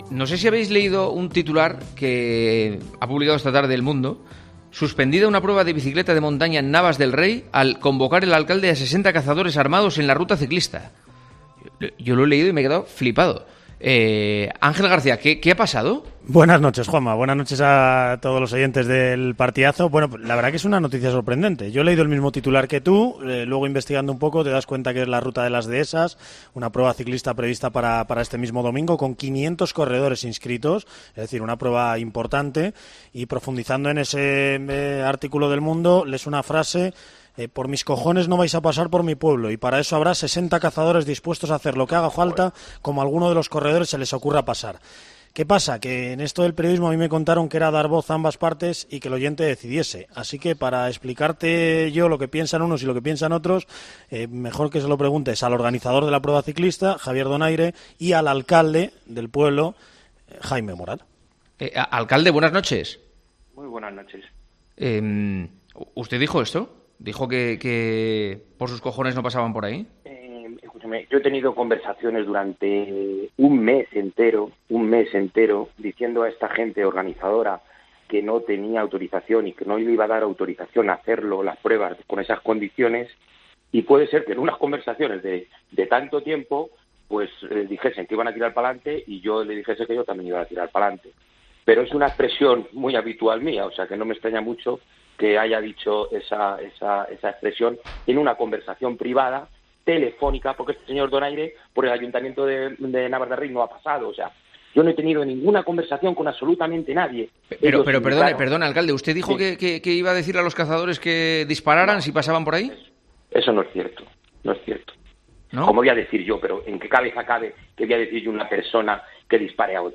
Hablamos con el alcalde de Navas de Rey, Jaime Peral